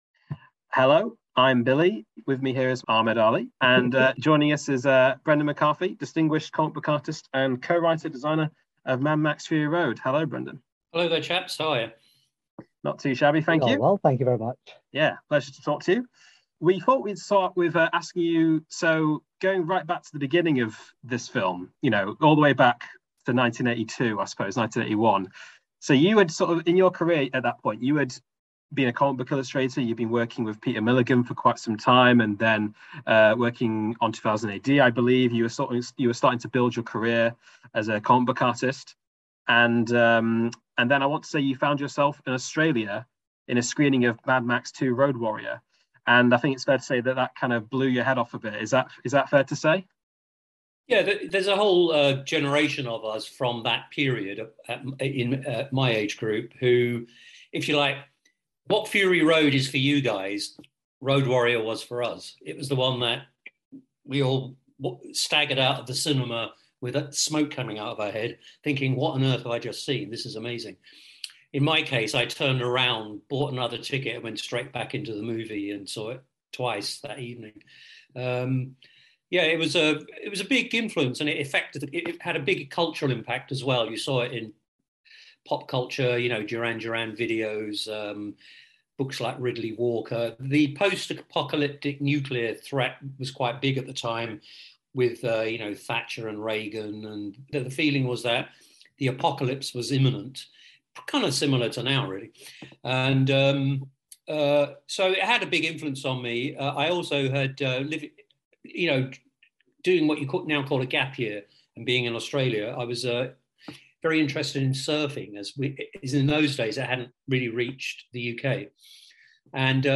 *Note: This interview was recorded on July 7th 2022.